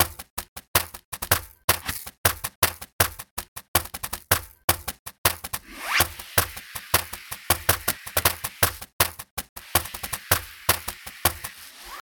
Brushed Ironing Board - Demo 1.mp3